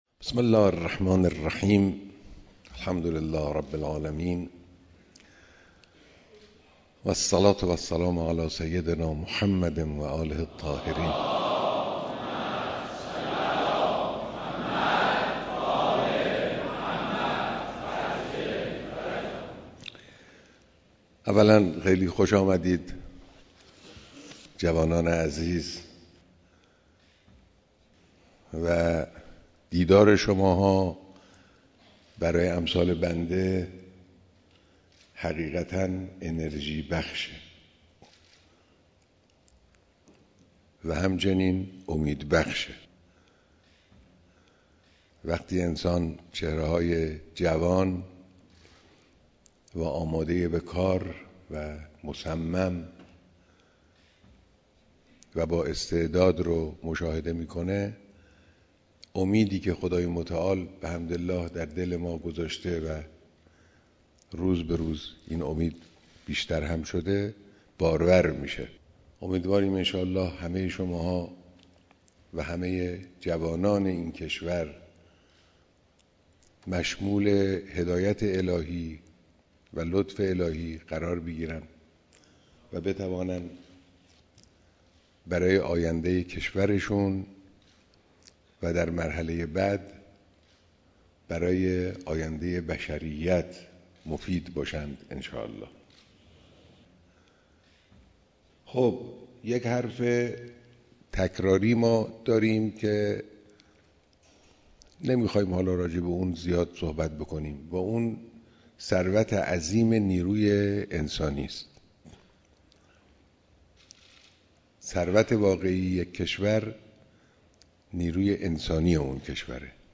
بیانات در دیدار جمعی از استعدادهای برتر علمی